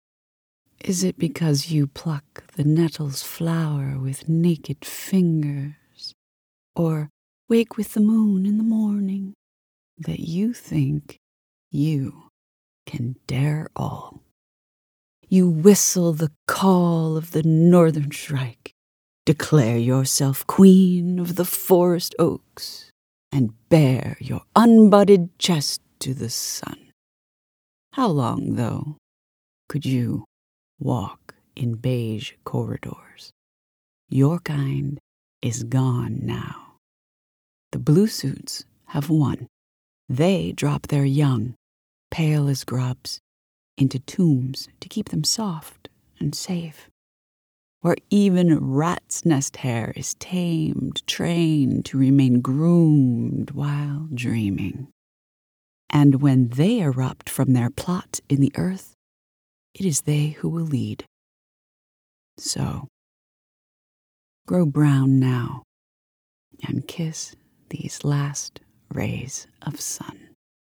Stinging Voices pairs a poet with a professional actor to bring the experience of enjoying poetry to the modern stage.